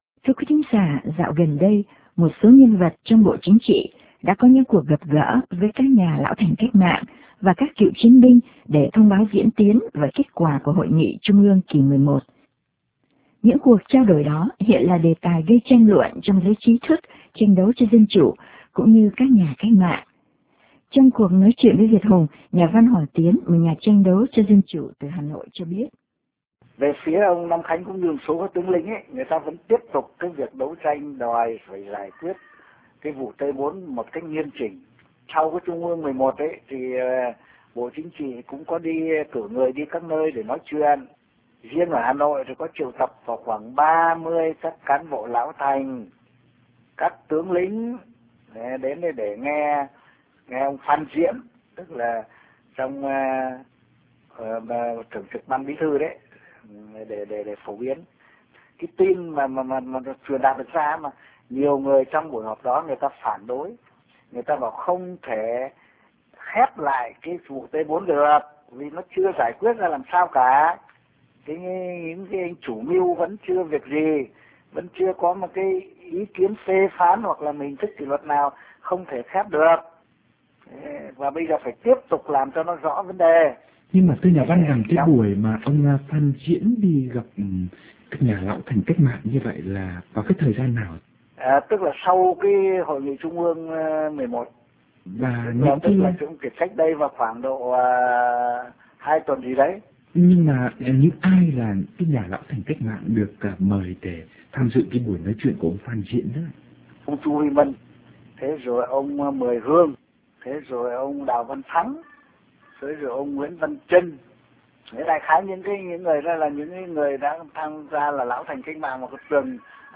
Another voice from inside the VCP criticizing the Party totalitarism as the cause of a backward Vietnam: former Vietnam's deputy minister of economy, professor Tran Van Ha. Q&A